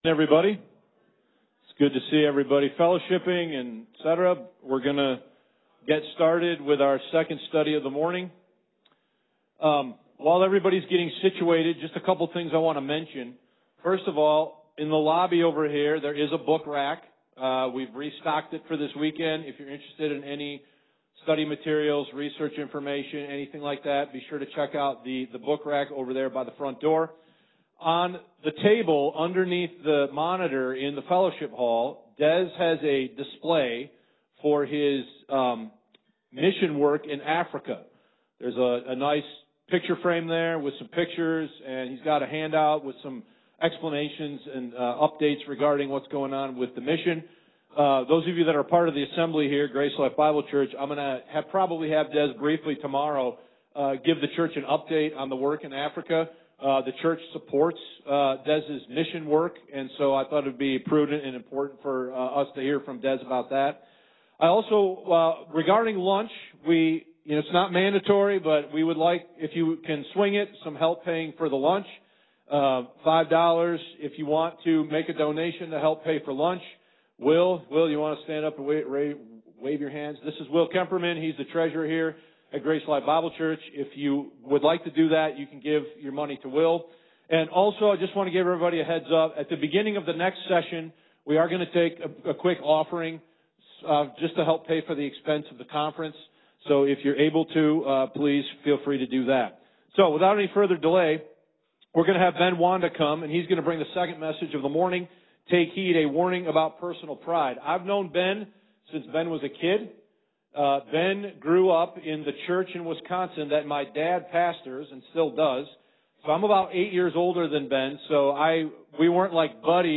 2025 West Michigan Grace Bible Conference | Beware & Take Heed: Understanding Paul’s Warnings To The Body of Christ